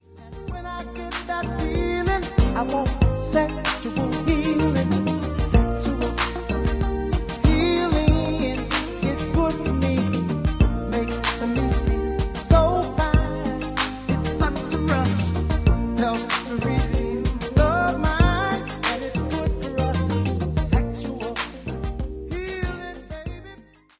Reduced quality: Yes
It is of a lower quality than the original recording.